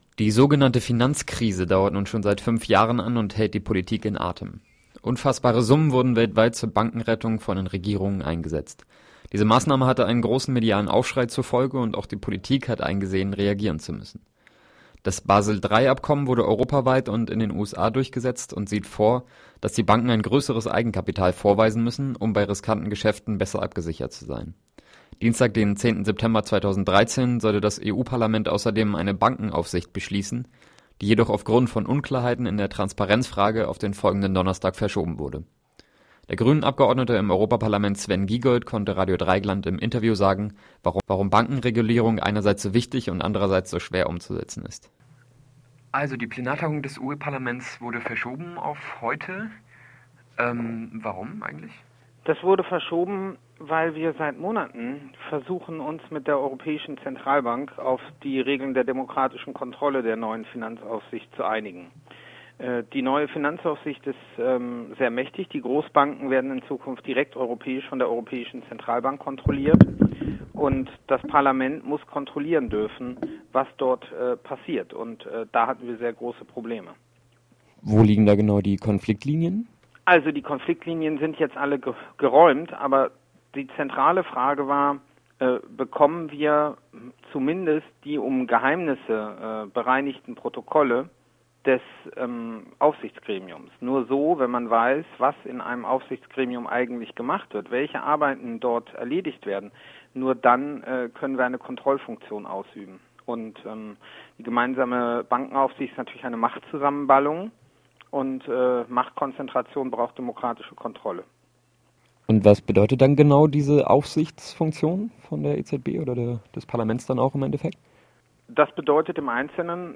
Interview mit dem grünen Europaparlamentsabgeordneten Sven Giegold zum Beschluss der Bankenaufsicht durch das Europaparlament und Bankenregulierungsbestrebungen seit Beginn der Finanzkrise.